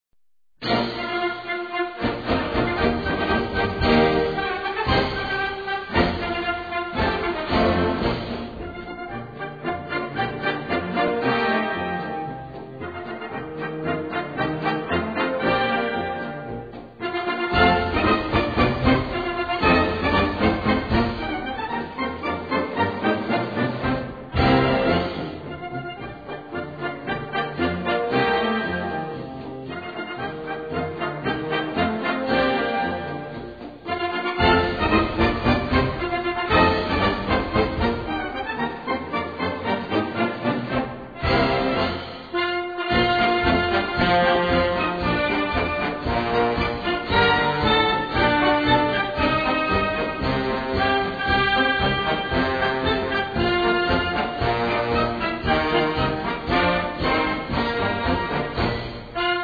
Gattung: Marsch
A5-Quer Besetzung: Blasorchester Zu hören auf